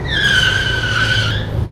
Car Squeals Sound
transport
Car Squeals